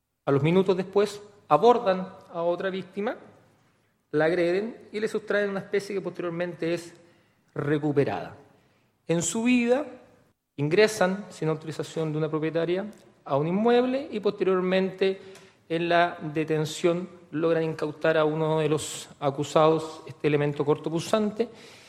hizo una relación de hechos en los alegatos de apertura.